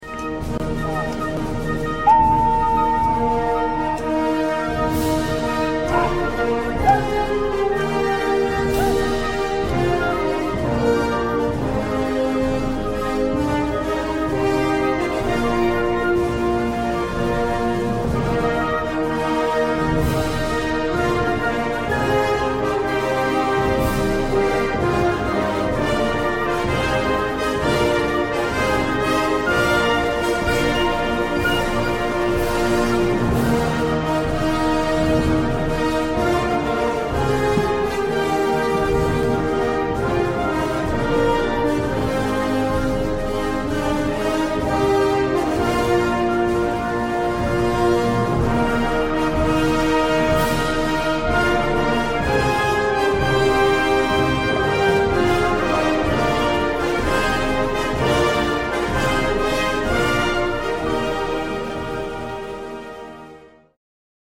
Trooping the Colour rehearsal 23rd